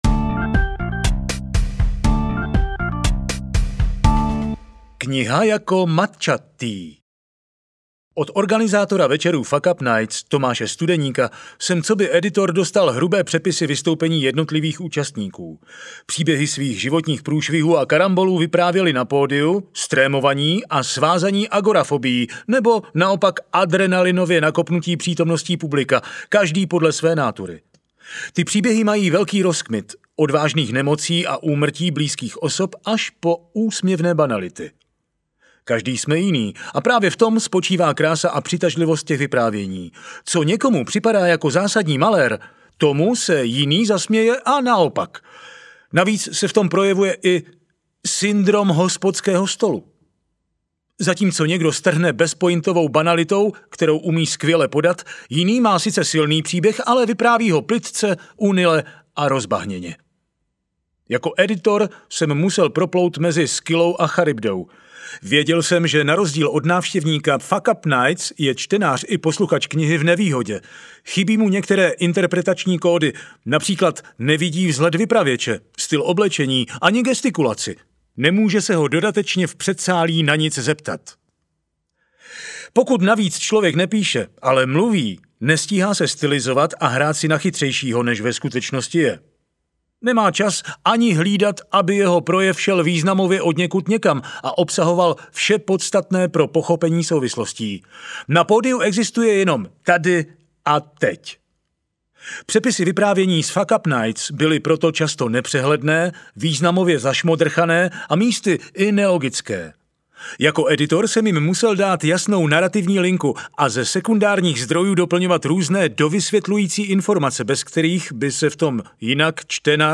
Velká kniha fuckupů audiokniha
Ukázka z knihy